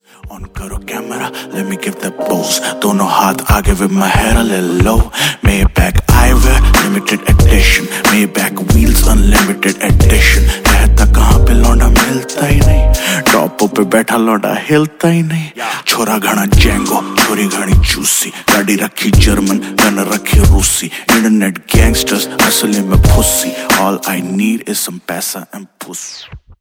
Punjabi sang